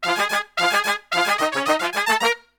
FUNK1 FM.wav